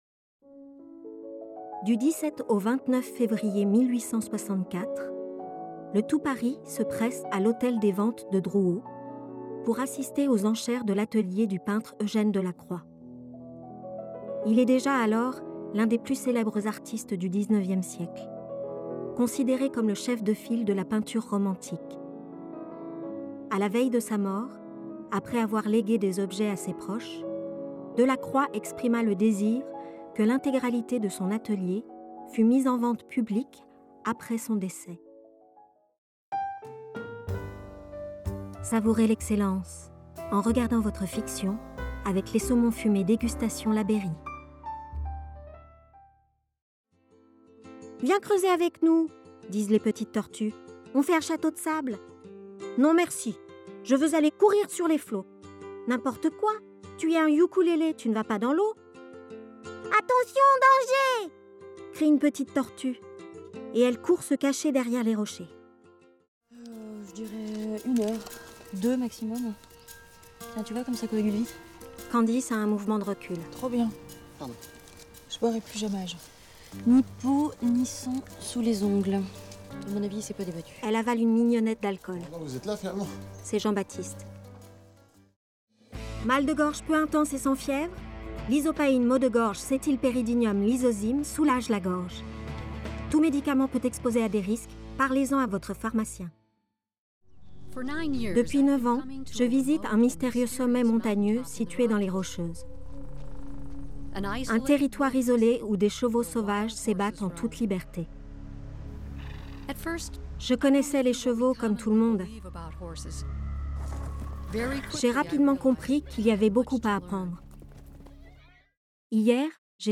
Voix off
Bande démo
narratrice
enfantine